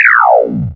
laseroff.wav